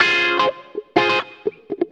WAV guitarlicks